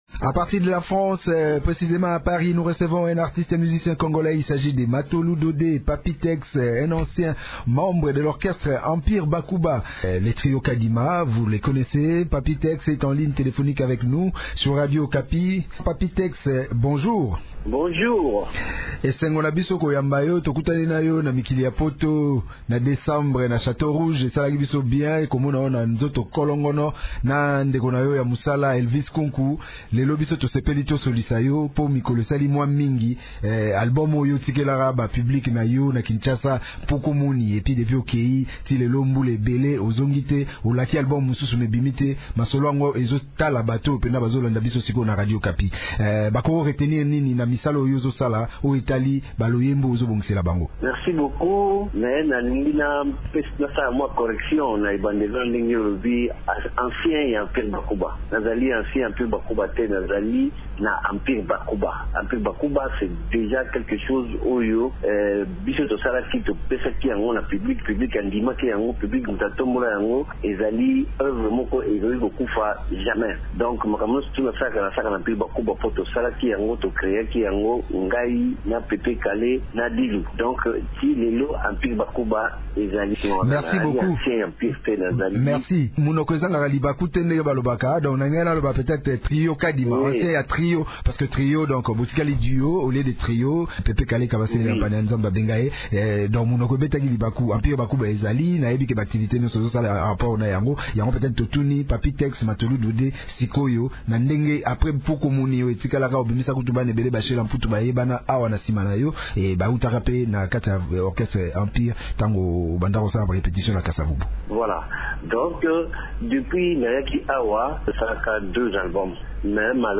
Sociétaire de l’orchestre Empire Bakuba, le musicien congolais Matolu Dodde, dit Papy Tex, a annoncé la sortie de son nouvel album intitulé: «The best of Papy Tex» au mois de septembre 2015. Dans un entretien accordé jeudi 16 avril à Radio Okapi depuis Paris (France), Papy Tex a indiqué cet opus est une compilation de ses meilleurs titres, dont «Wando et Mamie».